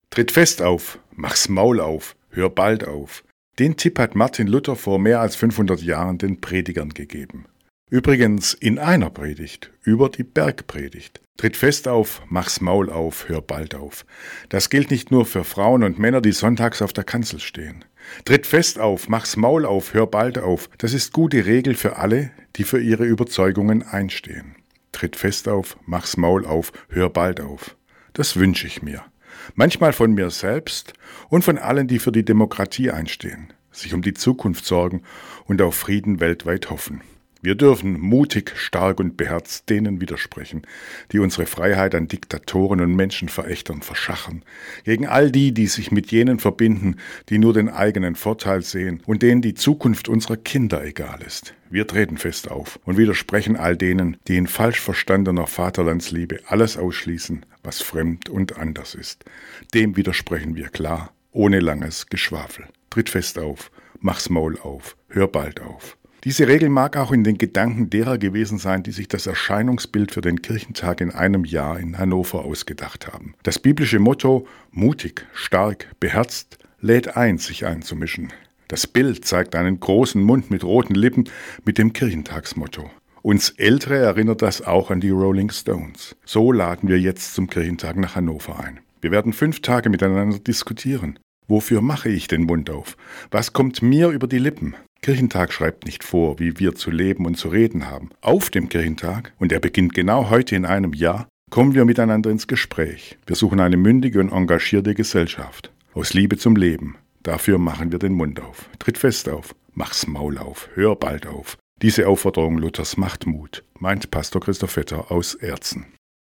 Radioandacht vom 30. Juni